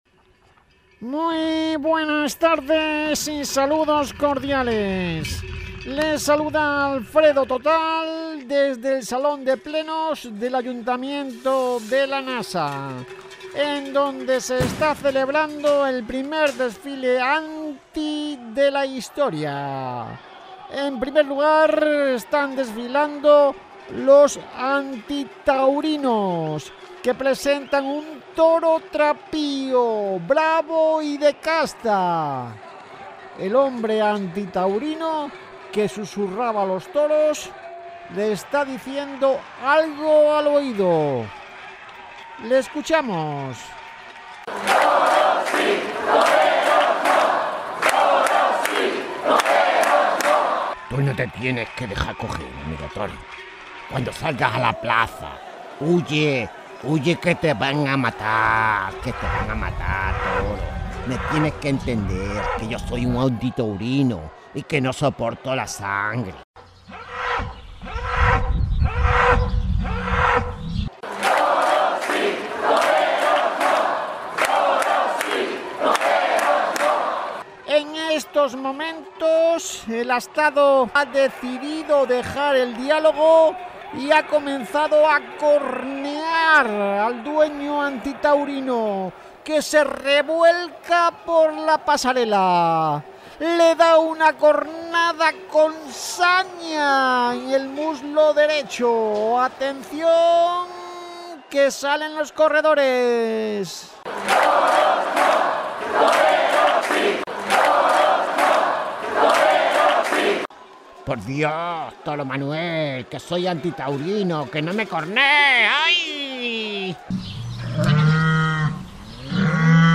gala_anti_guiones_television_radio_locutores_voiceovers.mp3